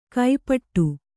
♪ kai paṭṭu